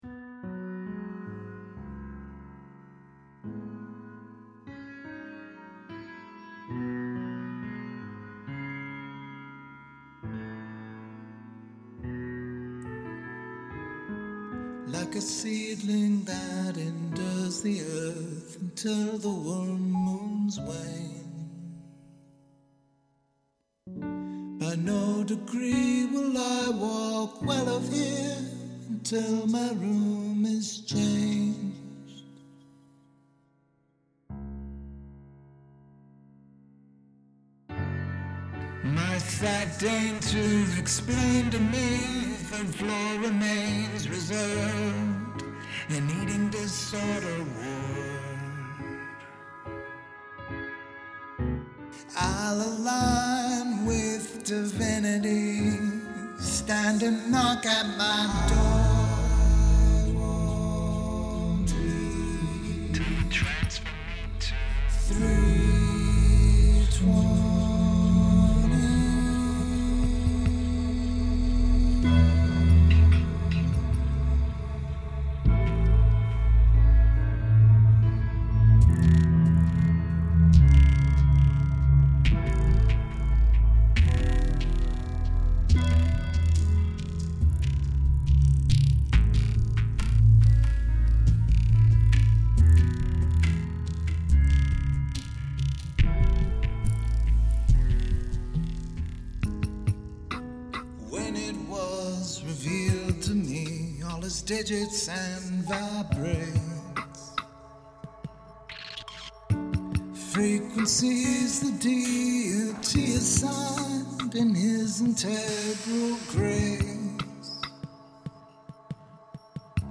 Ambience
gentle music, gentle ambience.
I like the overall atmosphere and doesn’t outstay its welcome.
2018 Forum post dreamy and strange.